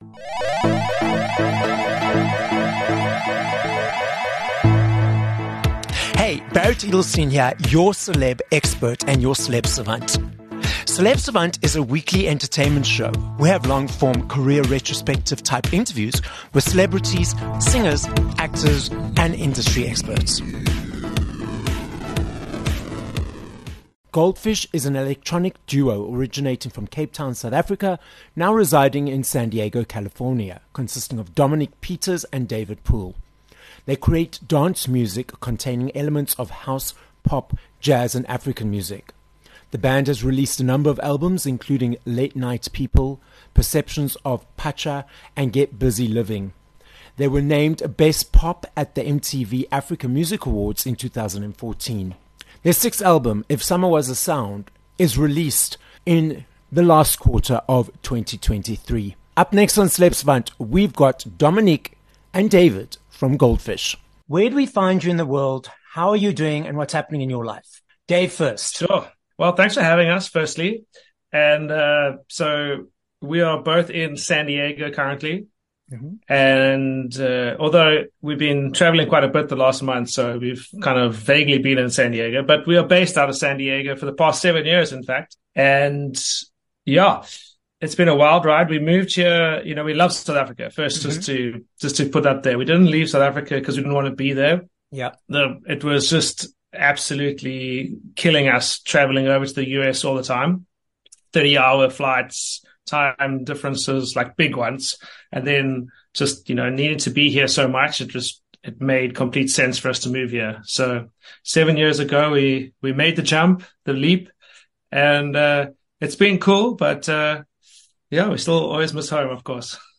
25 Sep Interview with Goldfish
Having left South Africa 7 years ago to live in San Diego for logistical reasons, David Poole and Dominic Peters from electronic duo Goldfish are the guests on this episode of Celeb Savant. They explain where the name Goldfish comes from, how they met, and why they decided to create dance music which infused African, pop, house and jazz music that led to a successful career in the music industry.